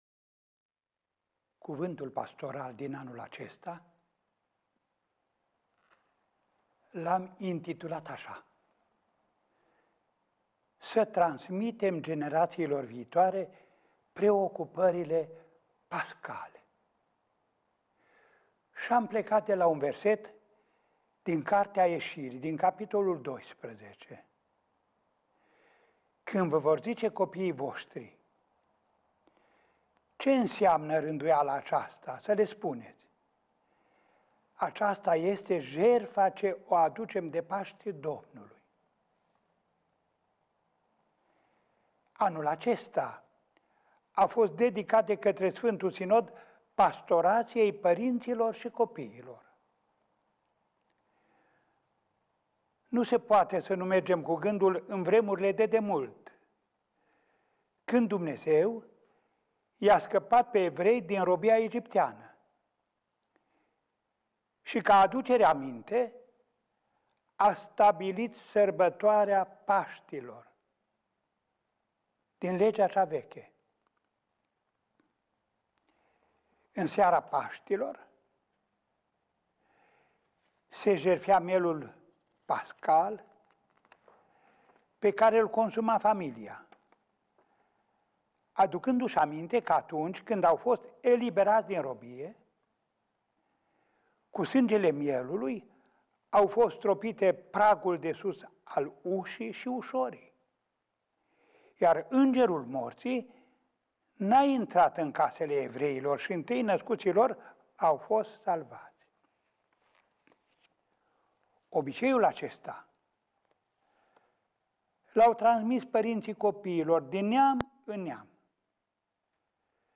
Să transmitem generațiilor viitoare preocupările pascale – Scrisoarea Pastorală a Arhiepiscopului Andrei